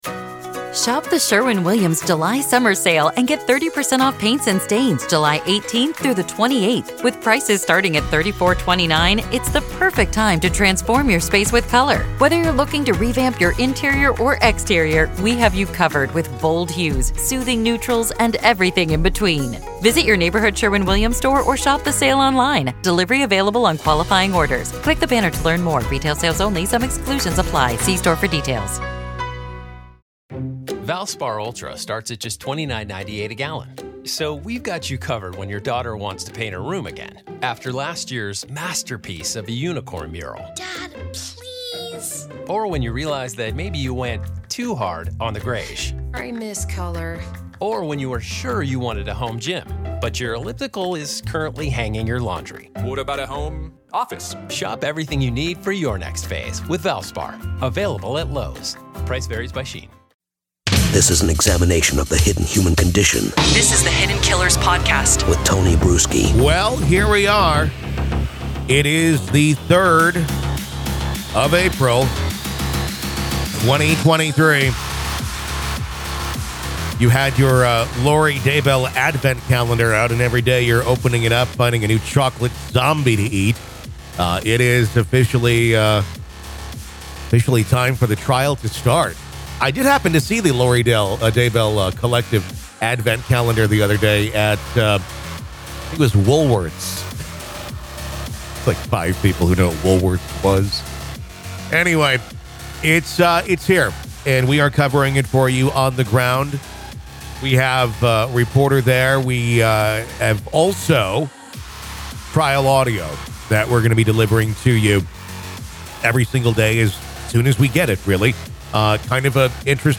As the long-awaited trial of Lori Vallow Daybell begins, Our podcast promises to provide gripping coverage and exclusive content, featuring insights from reporters on the ground and never-before-heard court audio.